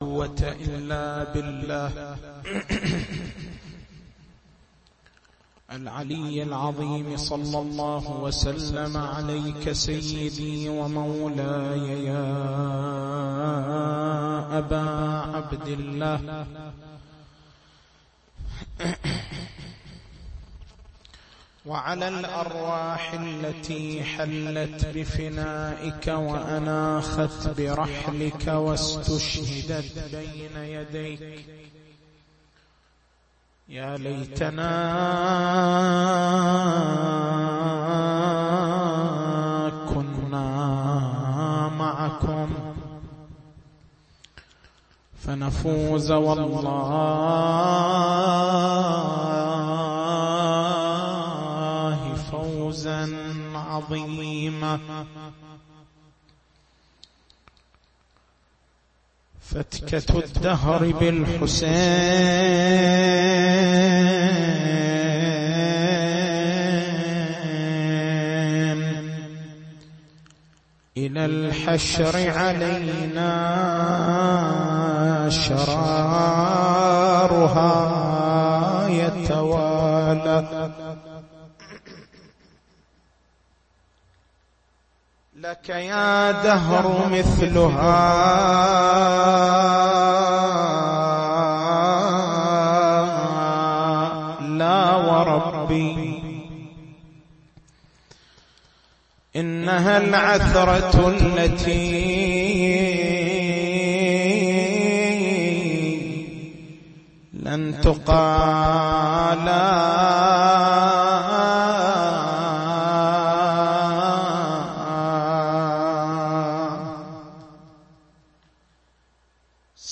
تاريخ المحاضرة: 29/09/1433 نقاط البحث: الفرق بين القبح الفعلي والقبح الفاعلي ما هو المقصود من الرياء؟ الميدان العقائدي الميدان الأخلاقي الميدان العبادي ما هي مراتب الرياء؟